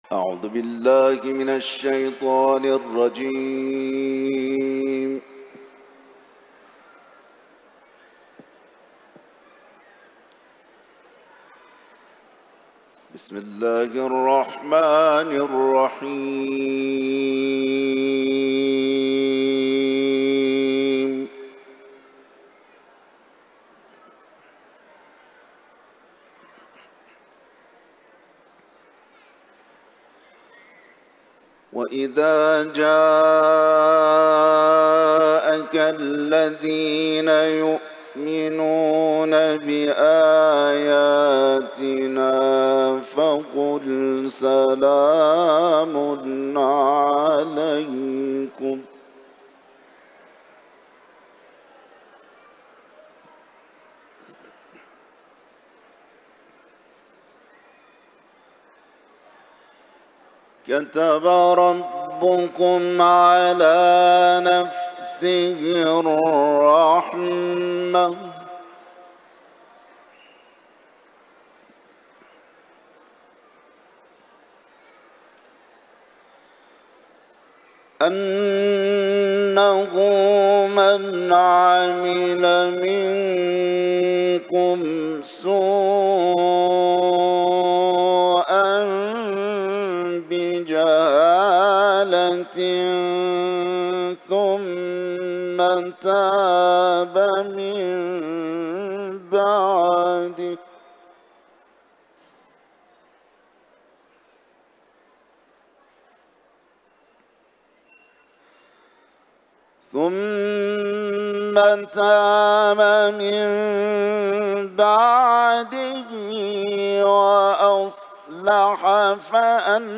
حرم مطهر رضوی